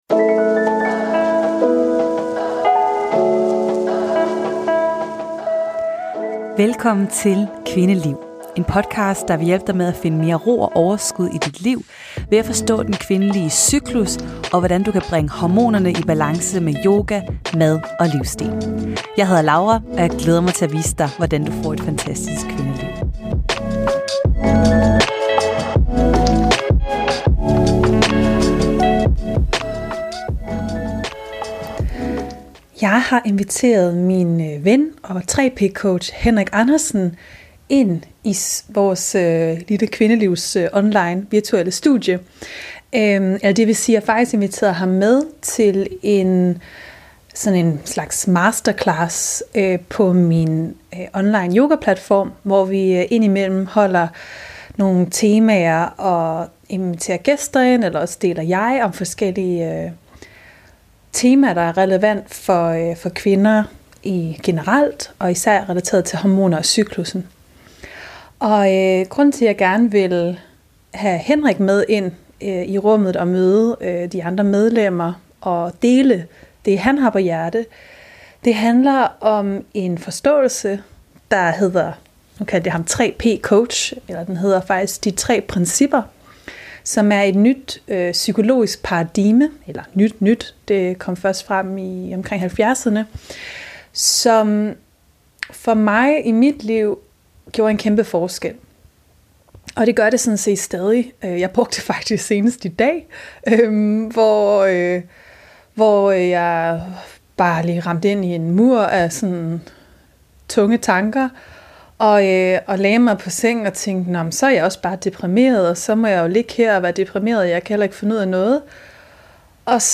Jeg håber, at du vil få glæde af at lytte med til vores uformelle snak om tanker, sind og de tre principper.